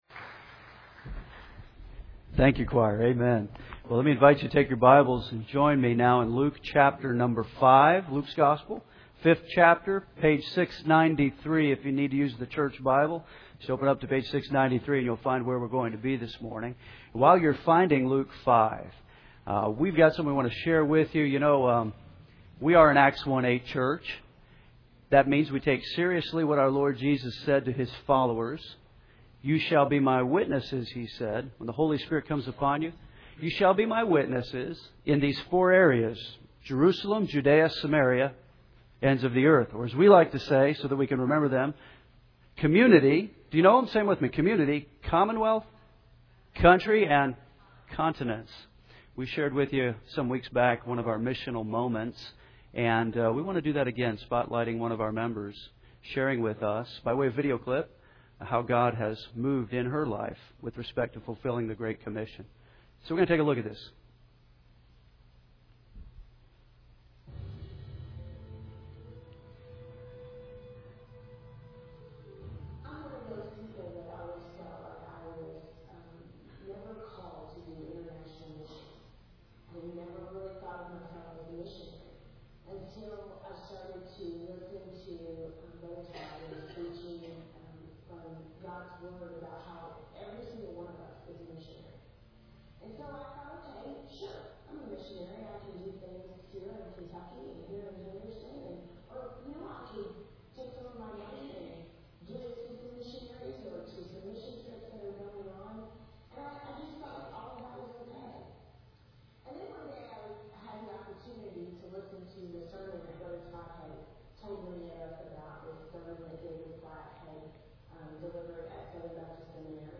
This is what we do here at Henderson’s First Baptist; expository preaching, teaching through books of the Bible.